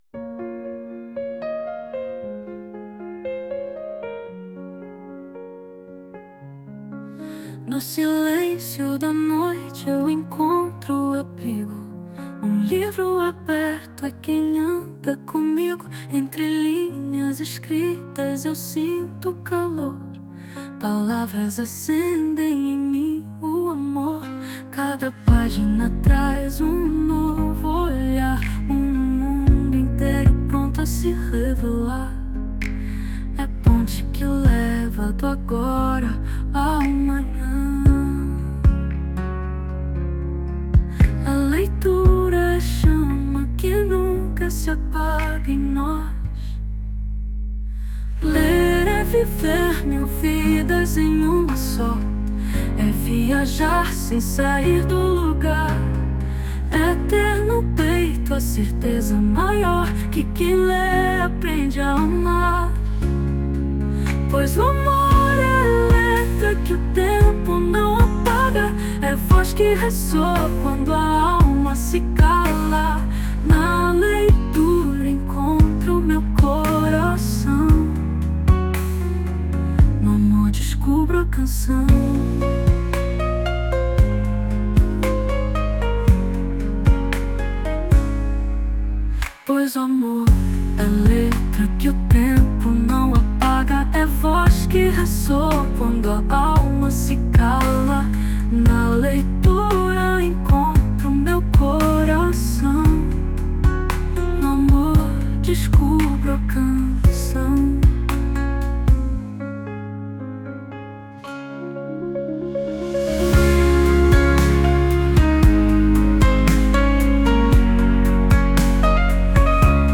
Piano
Voz